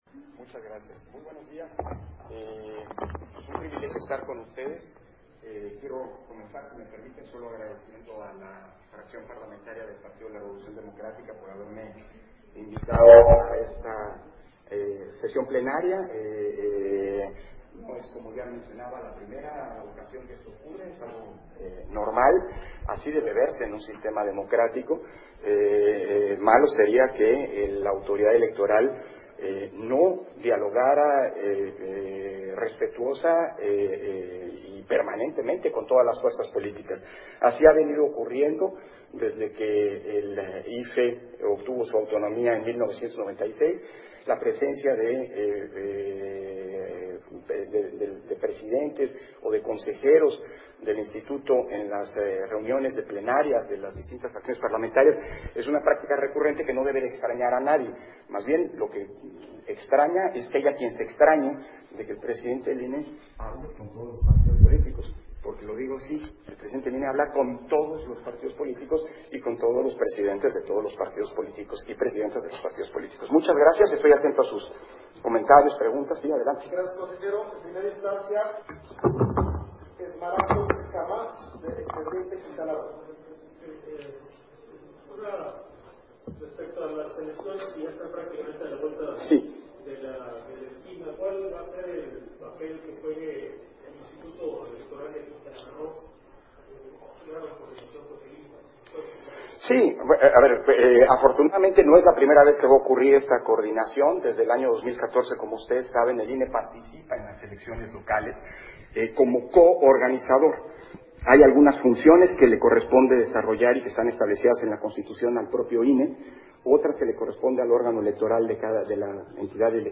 280122_AUDIO_-CONFERENCIA-DE-PRENSA-CONSEJERO-PDTE.-CÓRDOVA
Conferencia de Prensa de Lorenzo Córdova, al termino de su intervención durante la plenaria del Partido de la Revolución Democrática